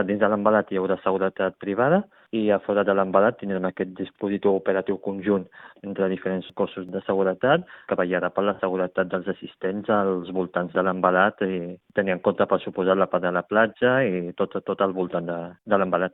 El tinent d’Alcaldia Soufian Laroussi, responsable de seguretat de l’Ajuntament, recalca que no s’escatimaran recursos perquè tothom pugui gaudir d’unes festes tranquil·les, i llança aquest missatge a la ciutadania.